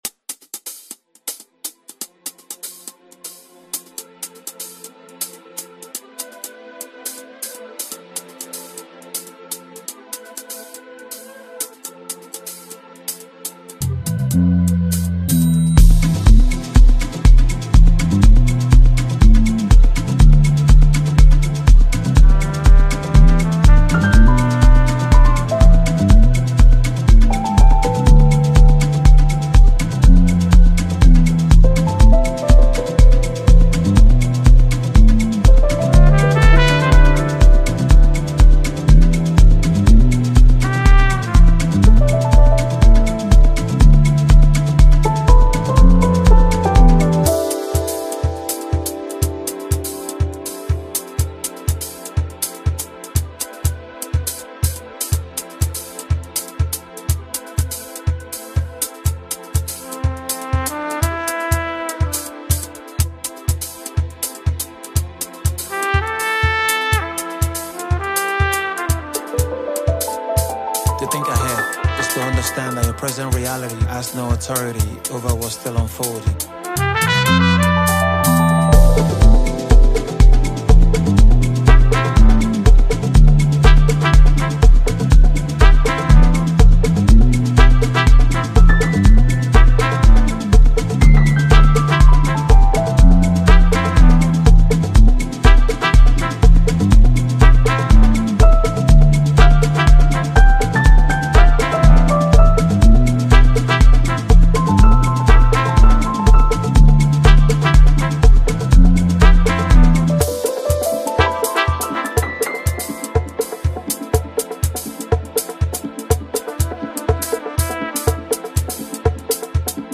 embracing the richness of jazz.